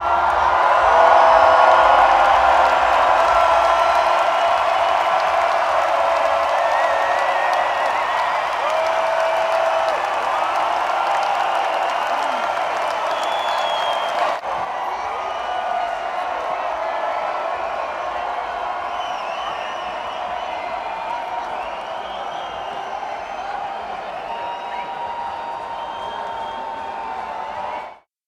cheer.ogg